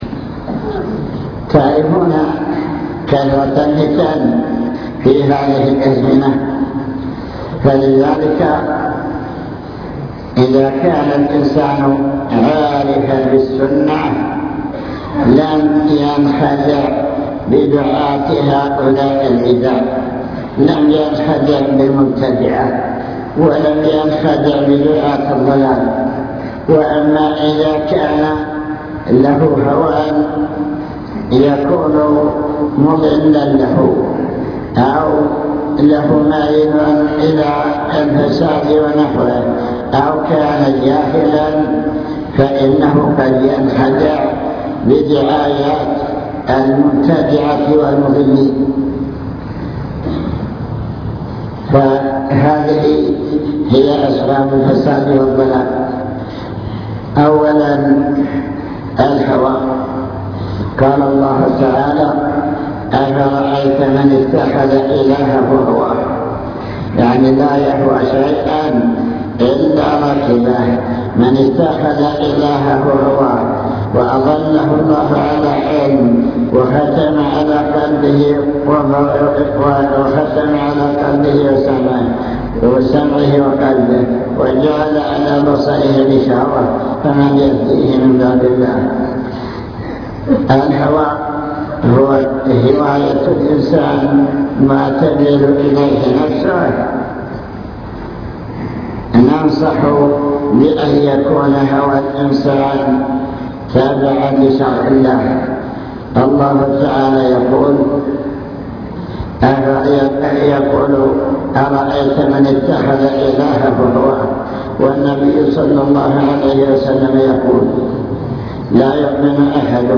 المكتبة الصوتية  تسجيلات - محاضرات ودروس  محاضرة في سراة عبيدة وصايا من الشيخ